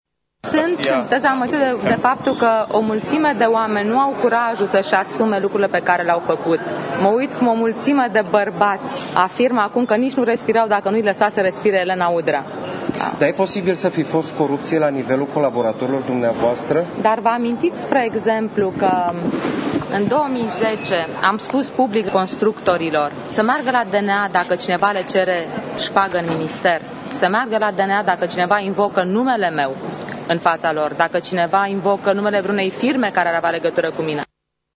La iesirea din sediul instanţei, Udrea a declarat că va aştepta decizia acasă:
udrea-declaratii.mp3